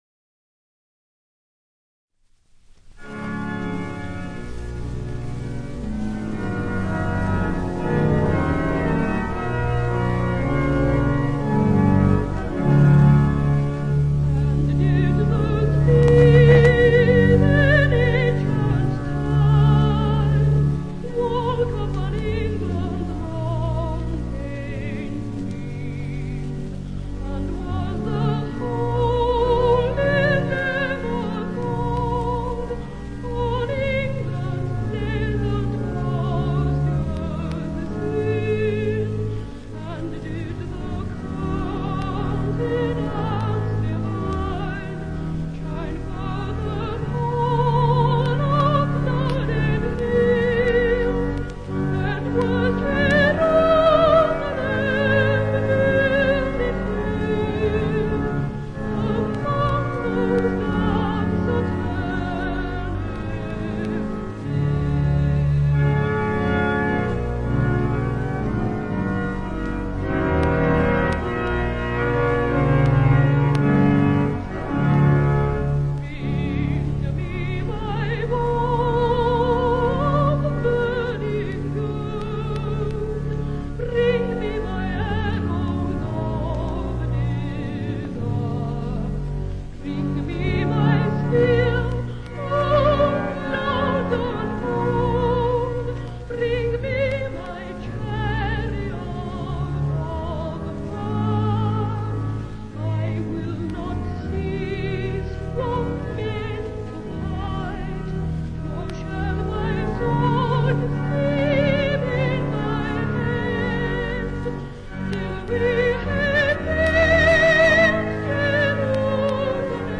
Great Boy Sopranos of the Early Twentieth Century
Rec. Temple Church, London, 24/2/32